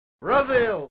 brazil-street-fighter-2-turbo-sound-effect-free.mp3